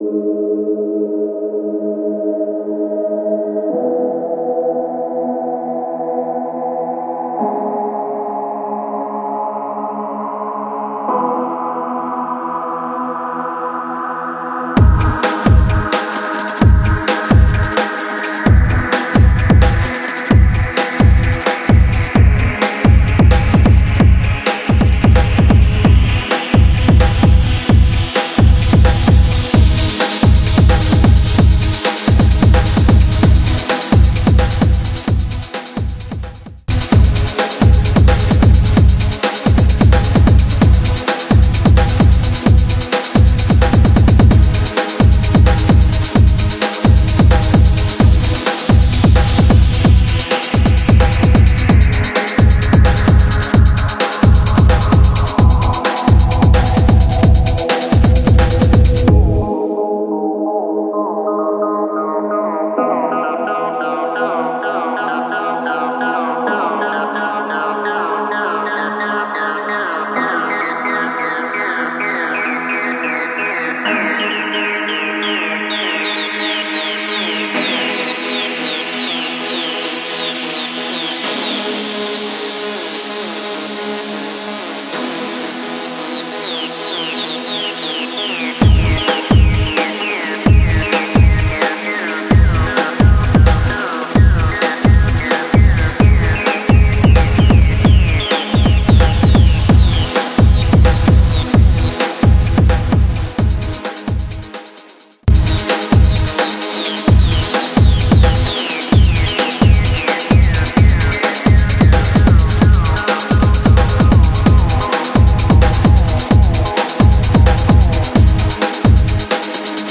Breaks